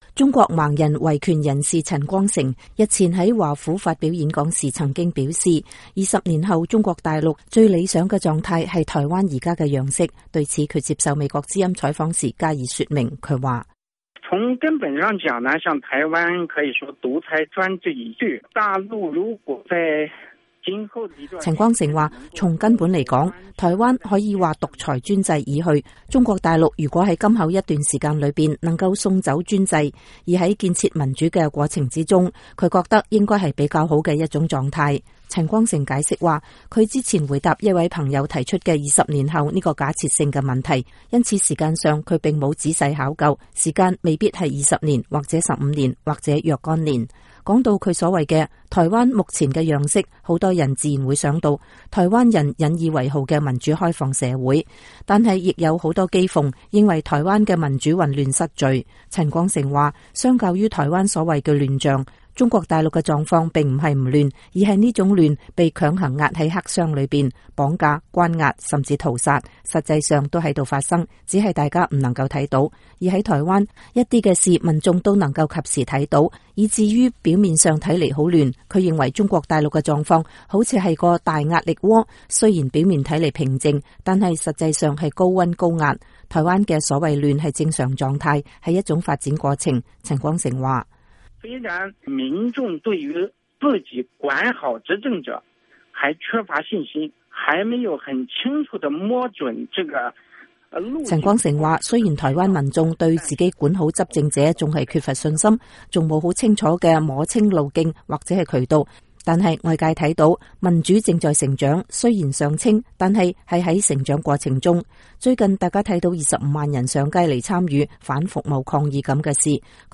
美國之音訪中國盲人維權人士陳光誠